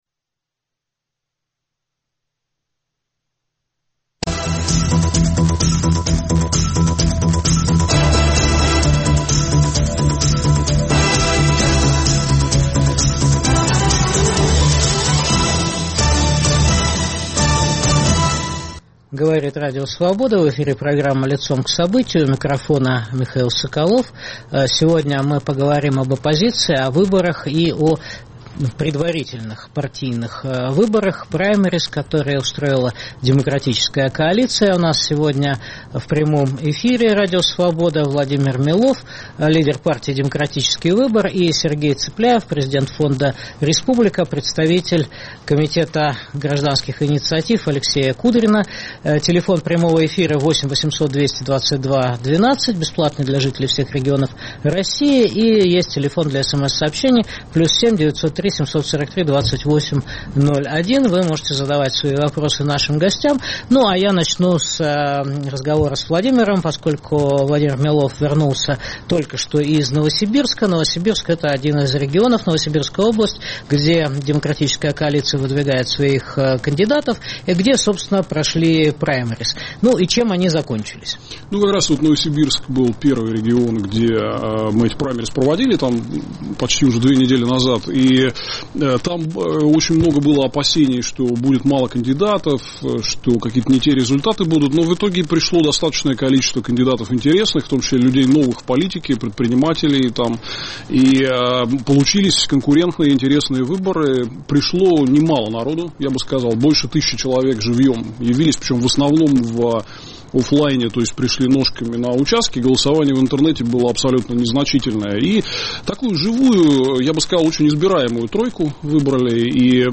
Итоги праймериз "Демократической коалиции" подводят лидер партии "Демократический выбор" Владимир Милов и президент фонда "Республика" политолог Сергей Цыпляев (Петербург).